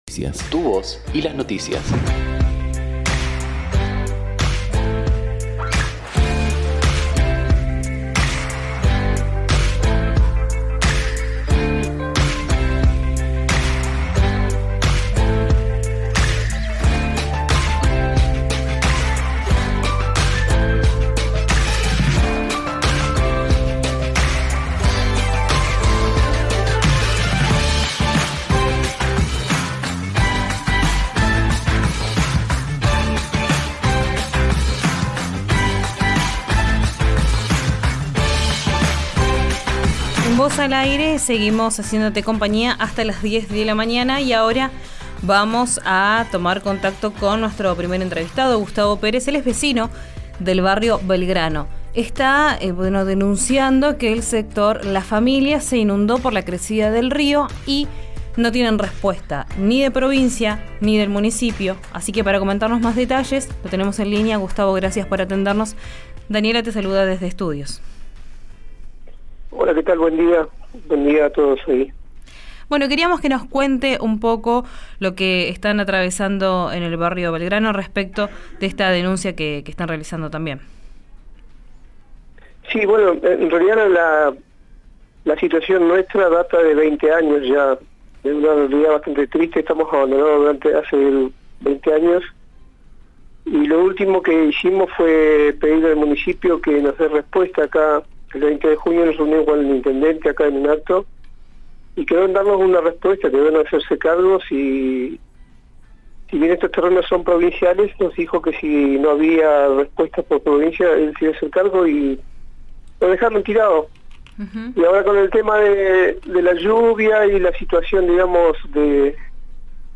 vecino del barrio Belgrano, por RÍO NEGRO RADIO: